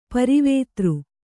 ♪ parivētř